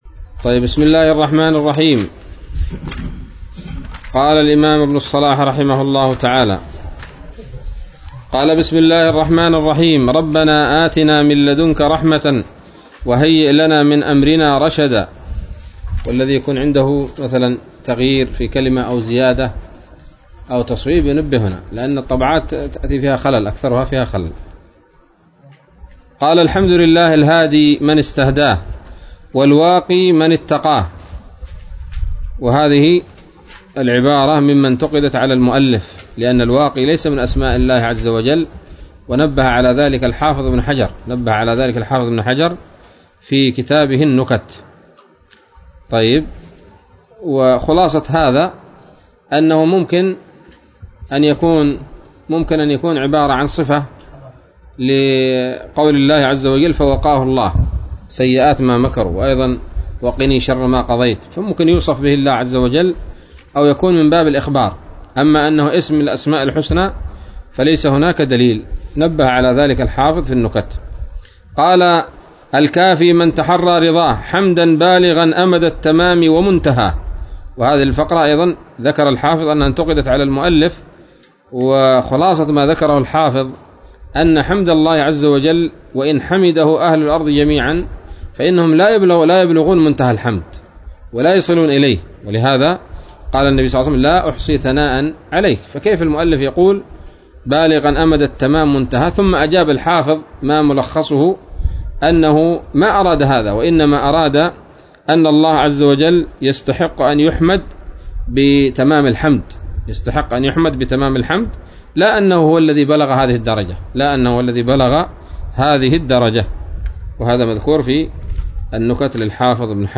الدرس الثاني من مقدمة ابن الصلاح رحمه الله تعالى